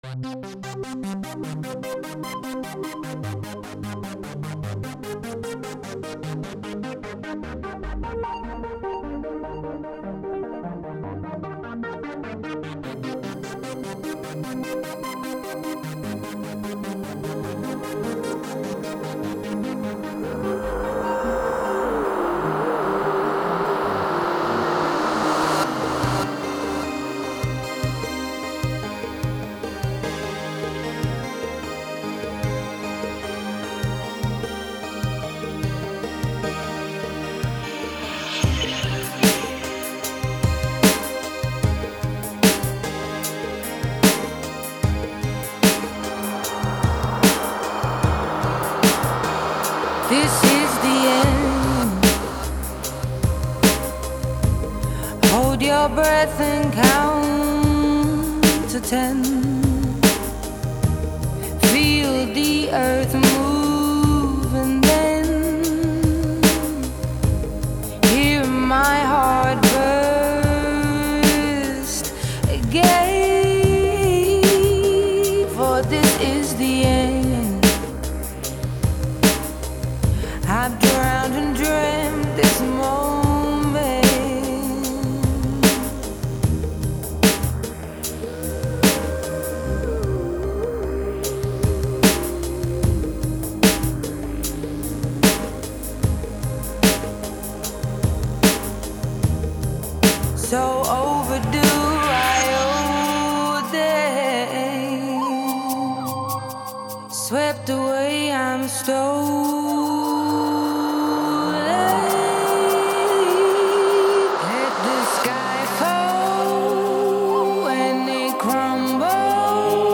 (Ремикс) PR